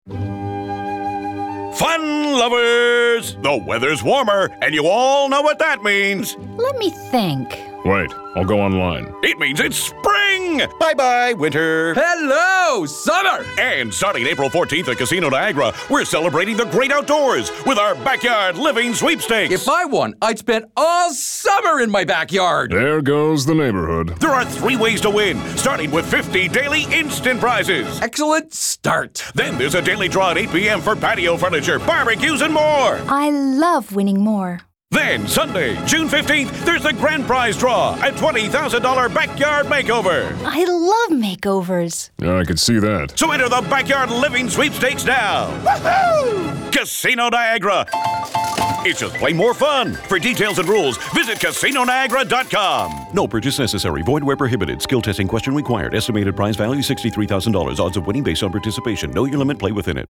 Affordable Professional Non Union Male Voiceover Talent
Link to Casino Niagara Radio Commercial.
Casino_Niagara_Radio_April_60.mp3